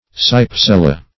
Meaning of cypsela. cypsela synonyms, pronunciation, spelling and more from Free Dictionary.
Search Result for " cypsela" : The Collaborative International Dictionary of English v.0.48: Cypsela \Cyp"se*la\ (s?p"s?-l?), n. [NL., fr. Gr. kypse`lh any hollow vessel.]